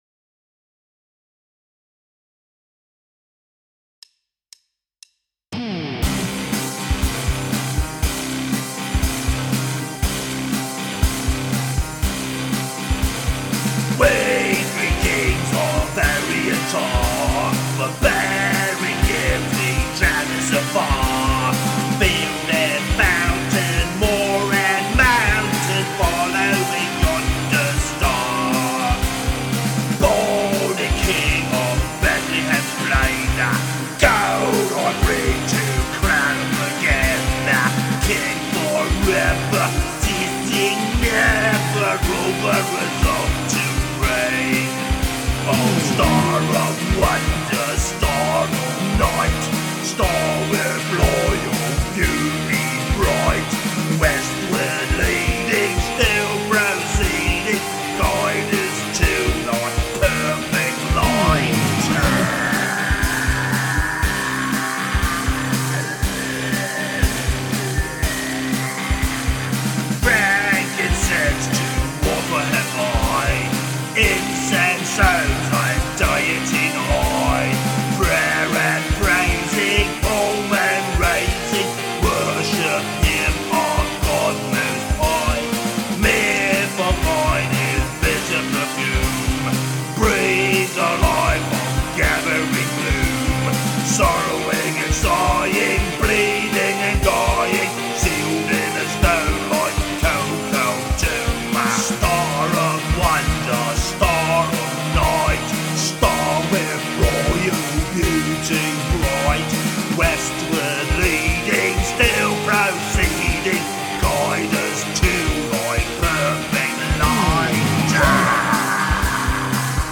Punk Version